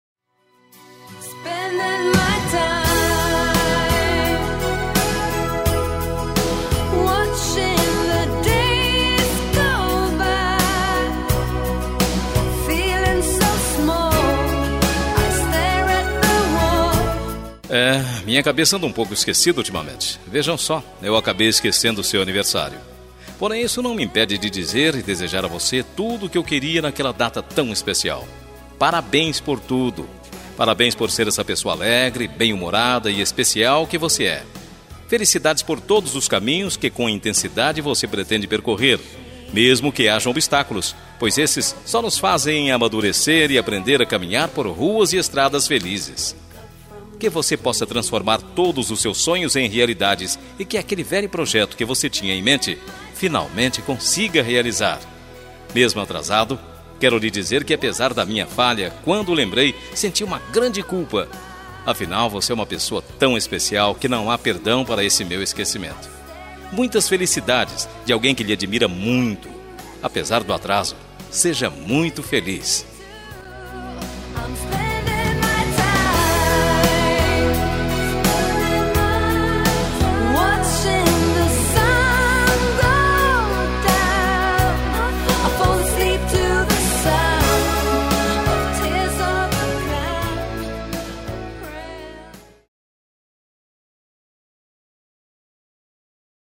Voz Masculino